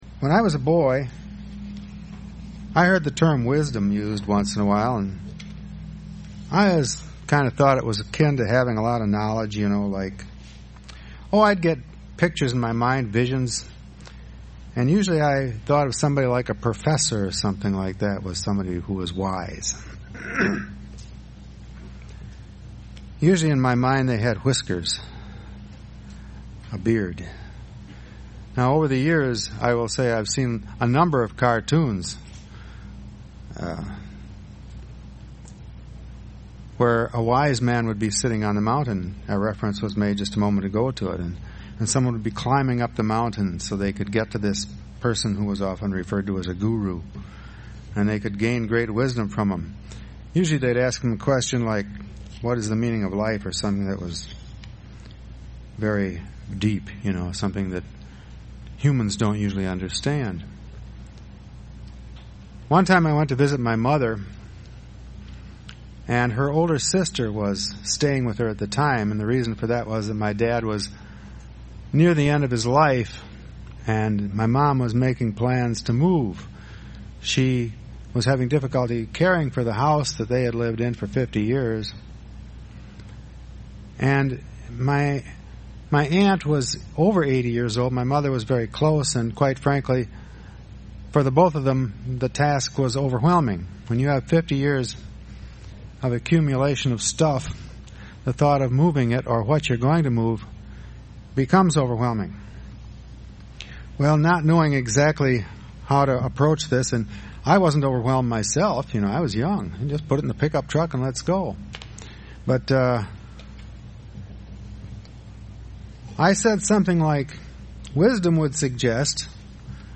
Given in Beloit, WI
Wisdom is an action Verb UCG Sermon Studying the bible?